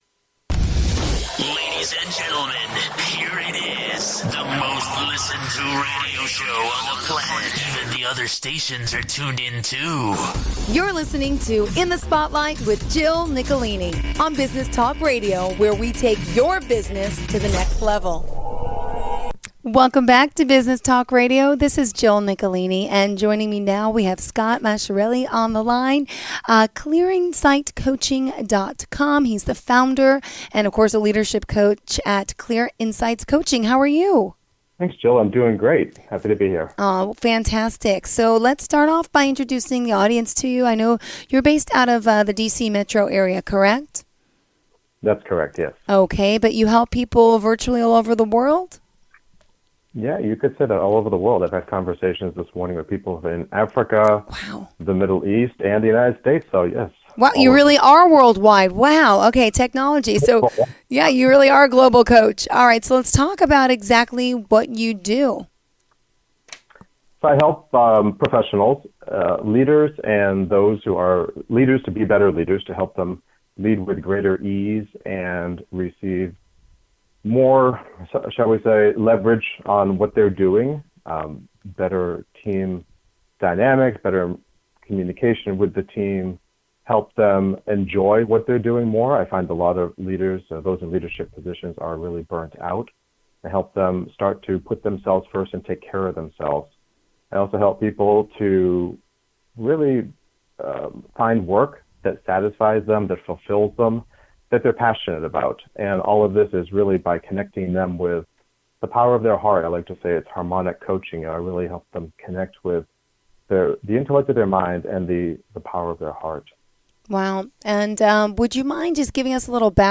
Biz-Talk-Radio-Show-1-Overview.mp3